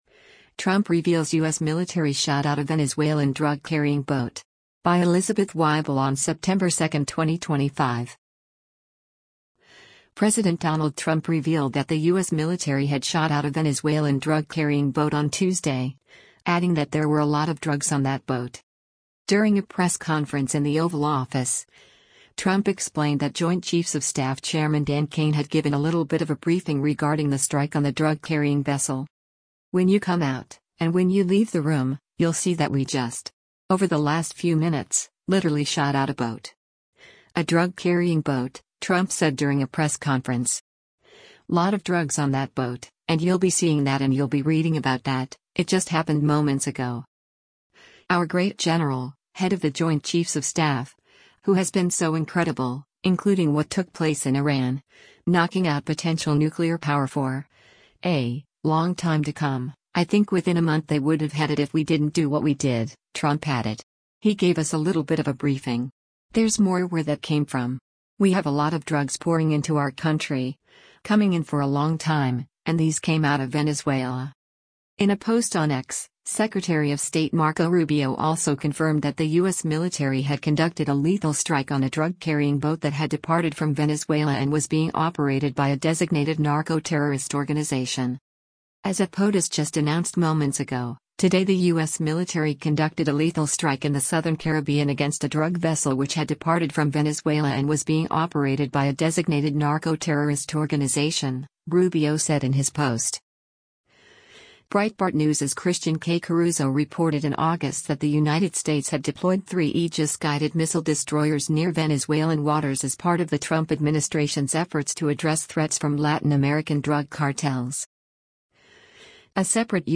During a press conference in the Oval Office, Trump explained that Joint Chiefs of Staff Chairman Dan Caine had given “a little bit of a briefing” regarding the strike on the drug-carrying vessel.